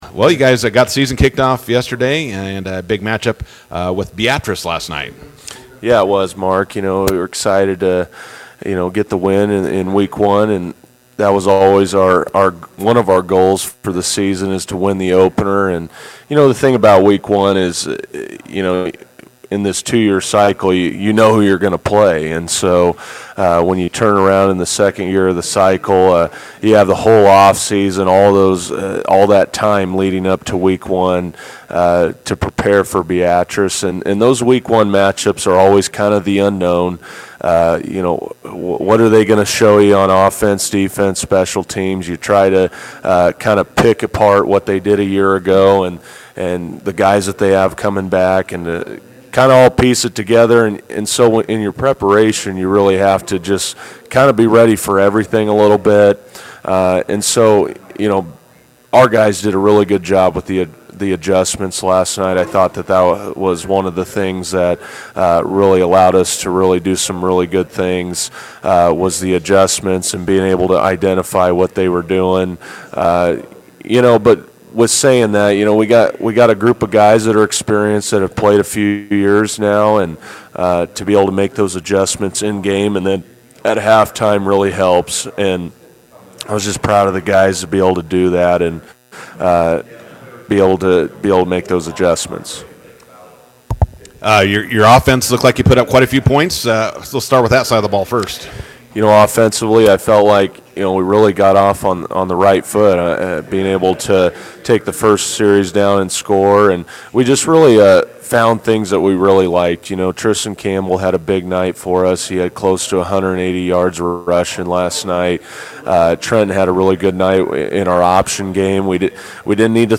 INTERVIEW: Bison football opens season with 41-15 win over Beatrice, prepare for road trip to Aurora this Friday.